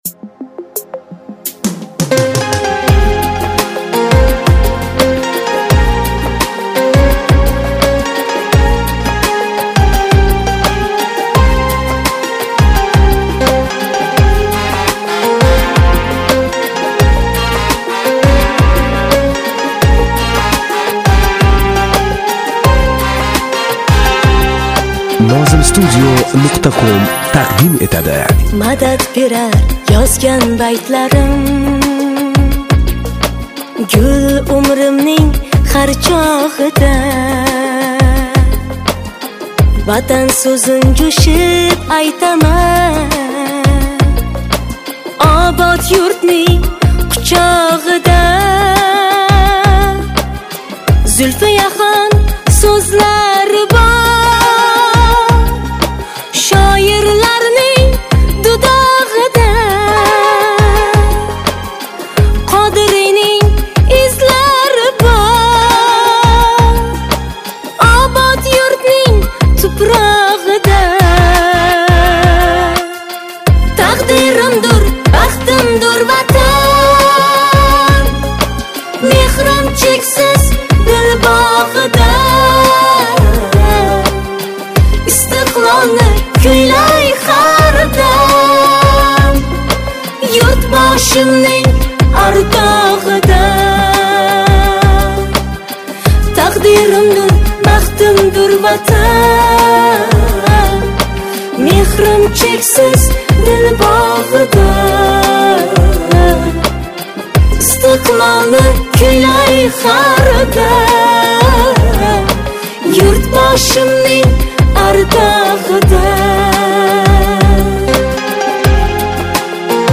UZBEK MUSIC [7816]